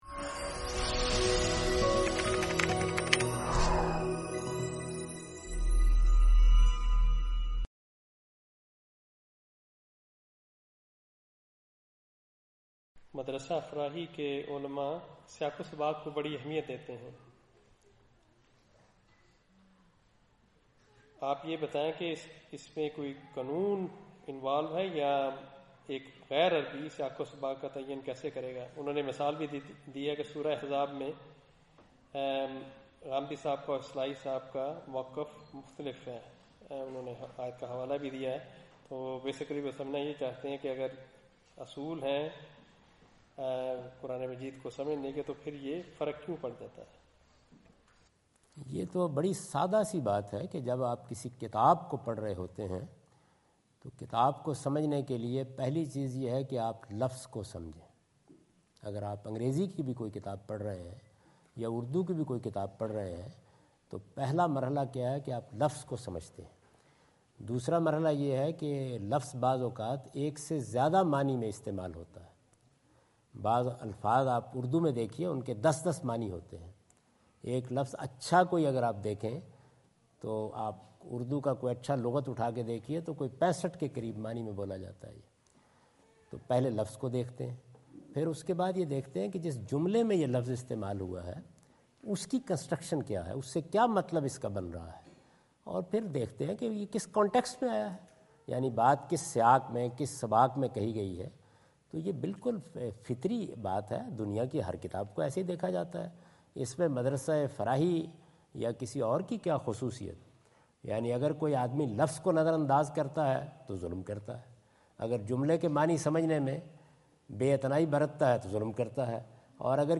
Javed Ahmad Ghamidi answer the question about "Importance of Context for Interpretation of Quranic Verses " during his visit in Canberra Australia on 03rd October 2015.
جاوید احمد غامدی اپنے دورہ آسٹریلیا کے دوران کینبرا میں "قرآنی آیات کی تشریح کے لیے سیاق و سباق کی اہمیت" سے متعلق ایک سوال کا جواب دے رہے ہیں۔